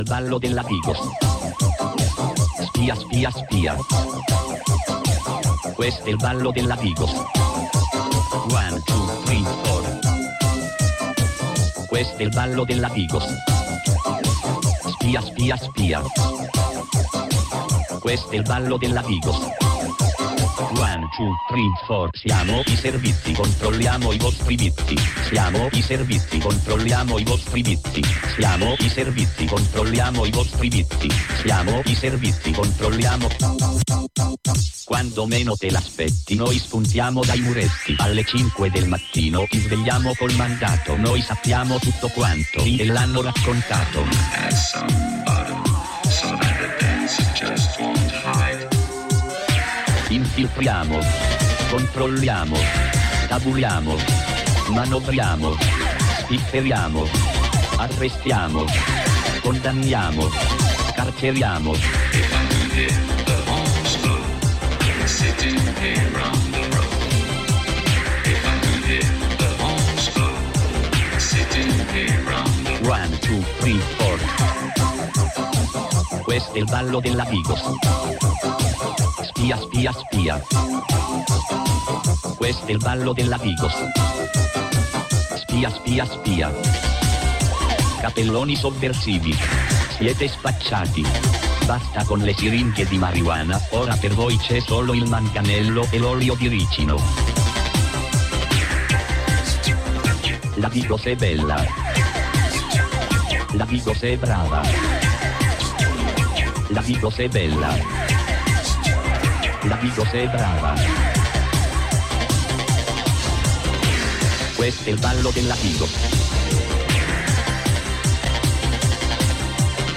in diretta telefonica
Collegamento con la Spagna
Selezione musicale